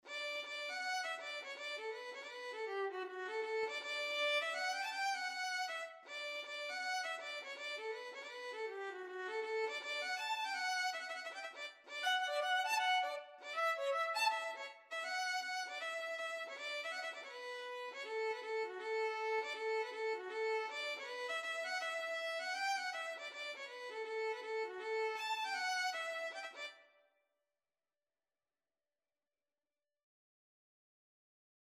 D major (Sounding Pitch) (View more D major Music for Violin )
4/4 (View more 4/4 Music)
Violin  (View more Intermediate Violin Music)
Traditional (View more Traditional Violin Music)